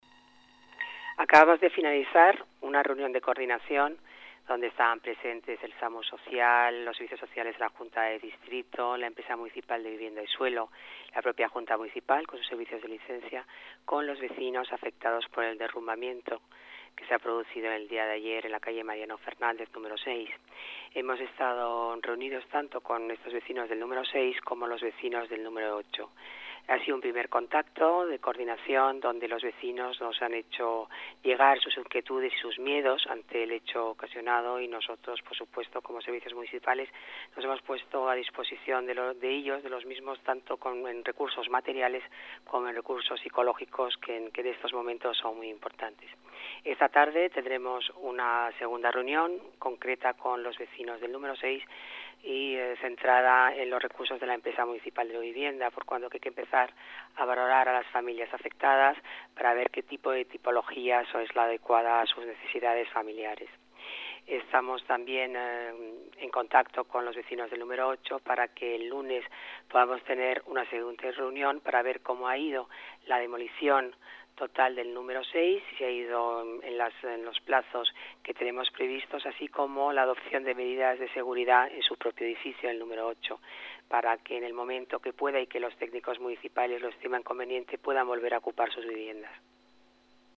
Nueva ventana:Paloma García Romero, concejala de Tetuán, informa sobre la reunión mantenida con los afectados